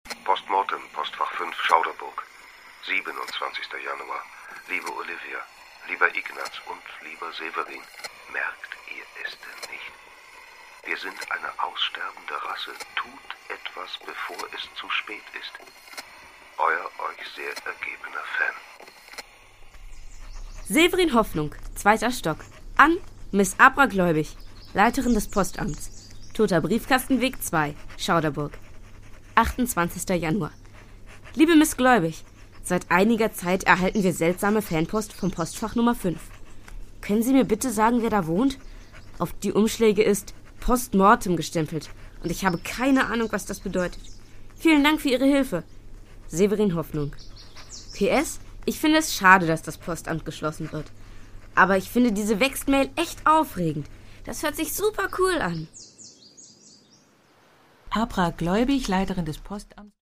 Produkttyp: Hörspiel-Download
Fassung: Hörspiel